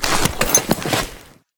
Ledge Climbing Sounds Redone